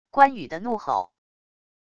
关羽的怒吼wav音频